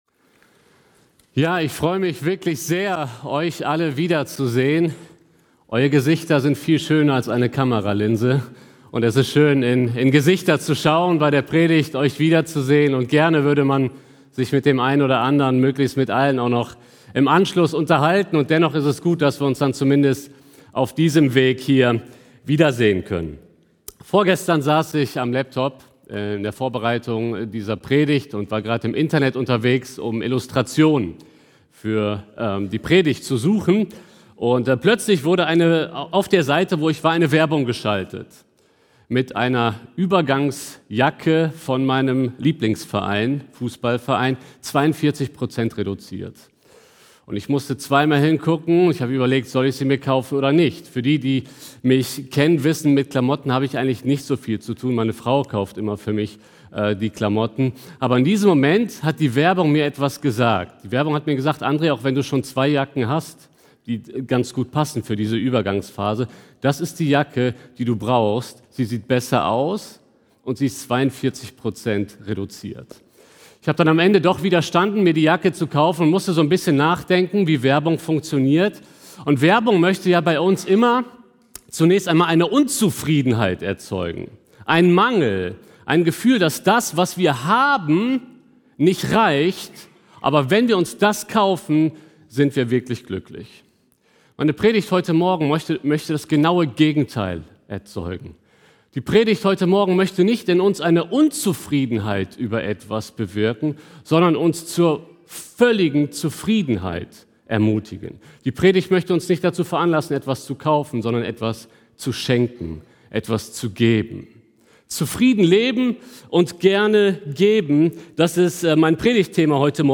Mai 2020 Predigt-Reihe